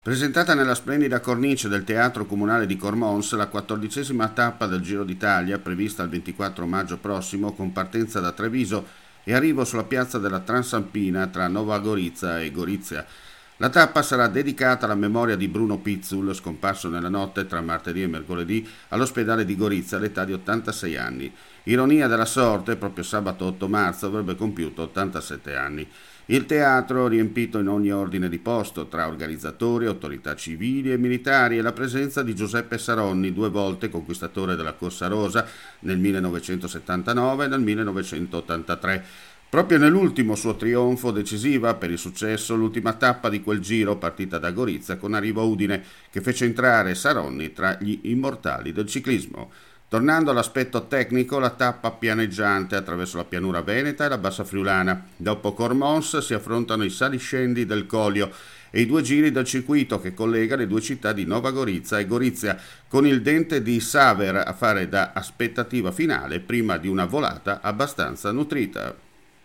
AUDIO SERVIZIO